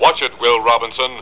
Lost in Space TV Show Sound Bites